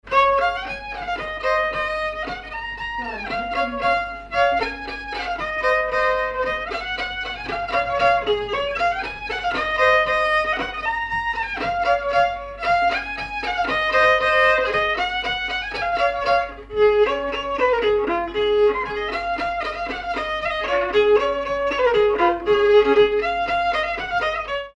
danse : marche
circonstance : bal, dancerie
Pièce musicale inédite